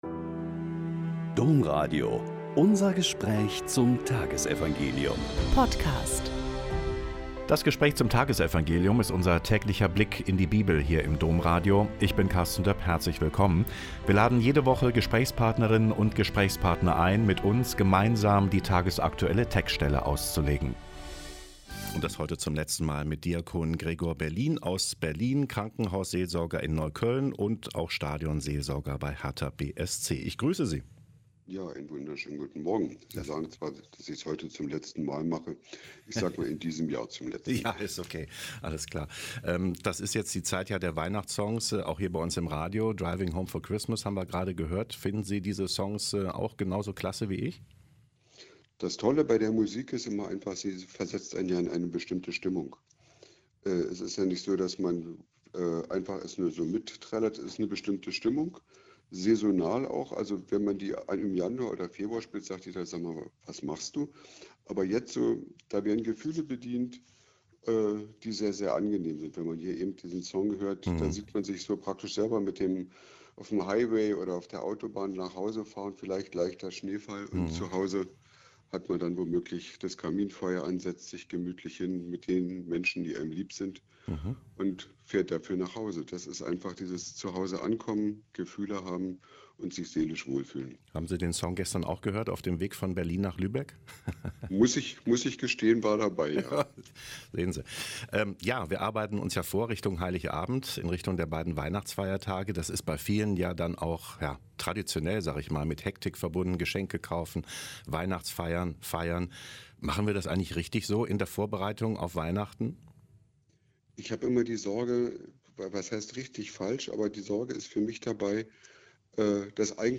Mt 9,35 - 10,1.6-8 - Gespräch